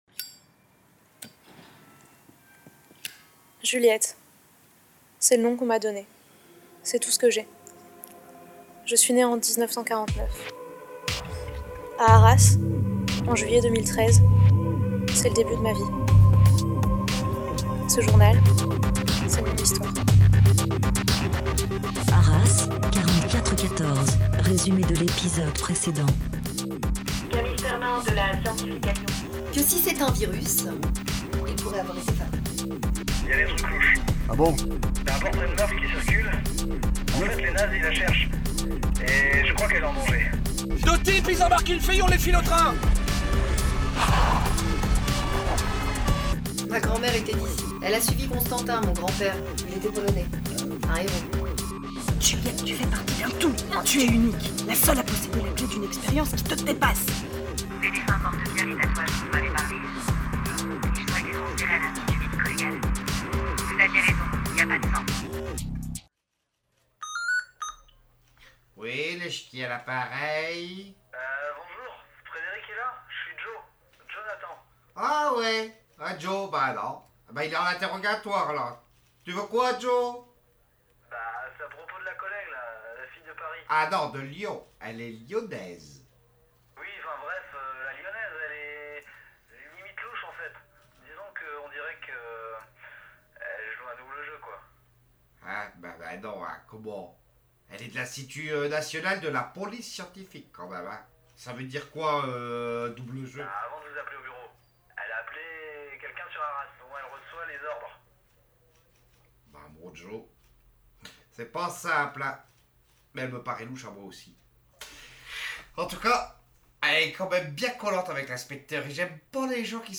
Arras 4414 sur PFM 99,9 !!! Arras 4414 , c'est une fiction radiophonique réalisée dans le cadre d'un atelier participatif par Platform lOkall et (...)